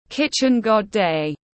Ngày ông Công ông Táo tiếng anh gọi là Kitchen God Day, phiên âm tiếng anh đọc là /ˈkɪʧɪn gɒd deɪ/
Kitchen God Day /ˈkɪʧɪn gɒd deɪ/